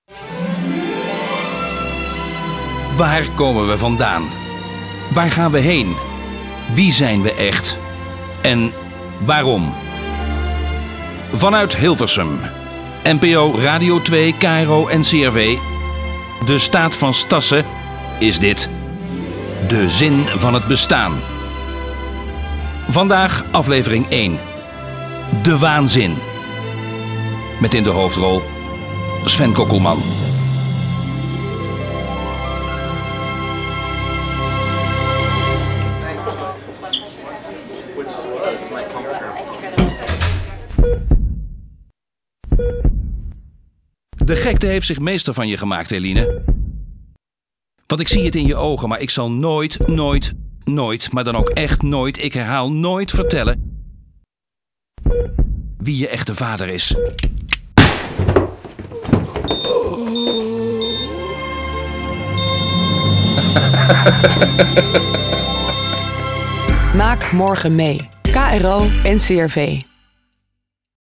Teaser: Dit is Wij | Een nieuwe dramaserie